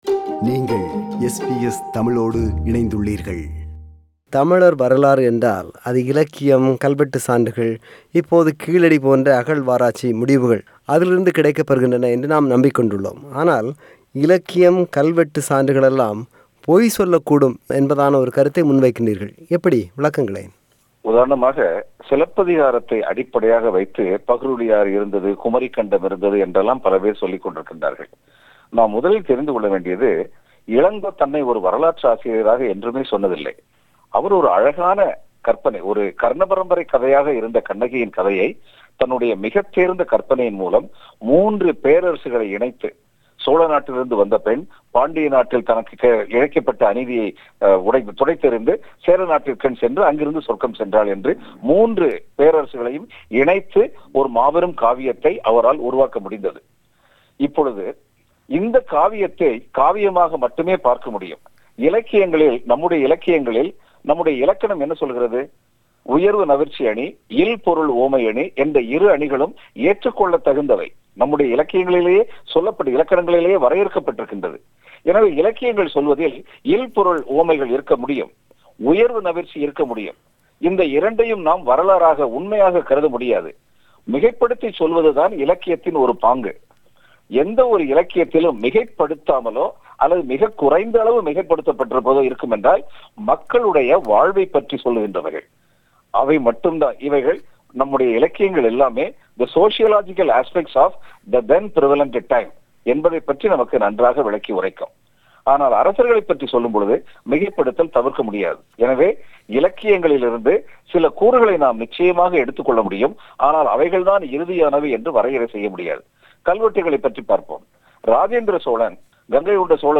ஆனால் தமிழ் மொழி, தமிழ் இனம், சாதி, தமிழரின் வணிகம், விமர்சனம், திறனாய்வு இல்லாத தமிழிலக்கியம் என்று பல அம்சங்கள் குறித்து மேடைகளில் கடுமையான விமரசனங்களை முன்வைத்து வருகிறார். அவரோடு ஒரு மனம் திறந்த உரையாடல்.